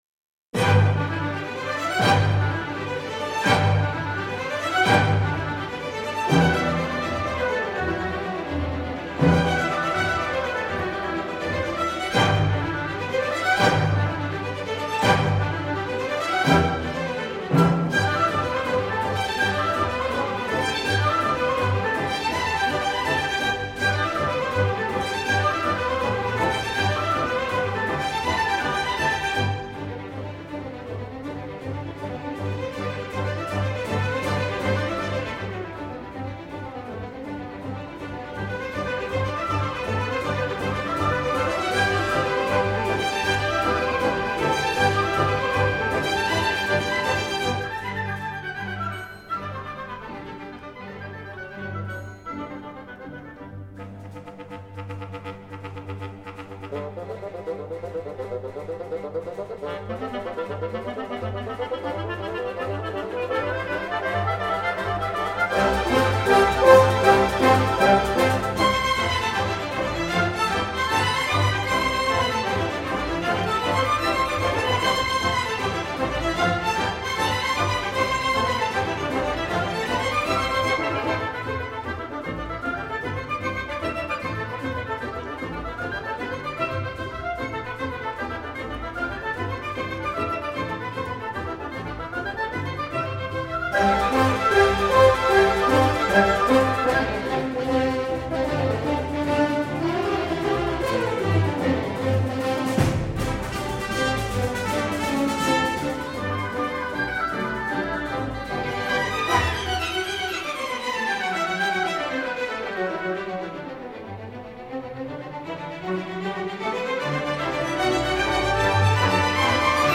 L’orchestre relève le défi avec succès.